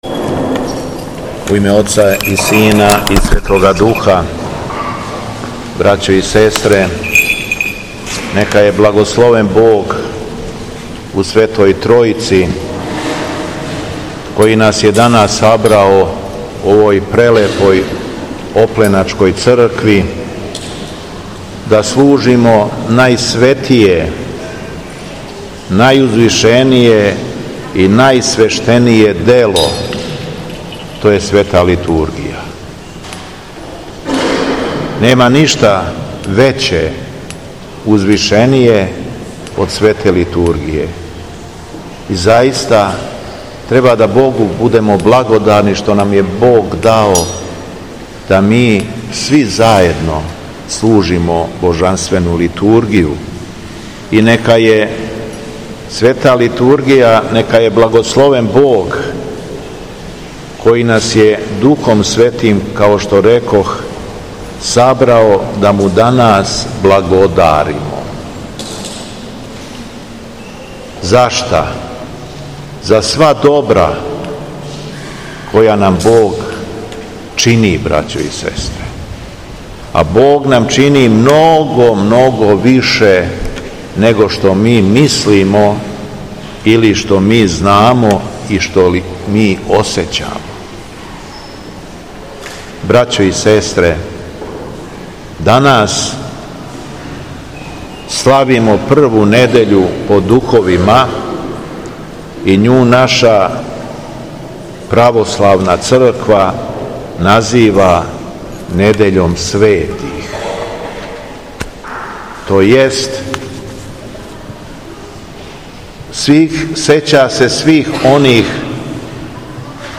Повод за литургијско сабрање био је фестивал хорова шумадијске епархије.
Беседа Његовог Високопреосвештенства Митрополита шумадијског г. Јована
После прочитаног Јеванђеља сабраном народу беседио је владика Јован: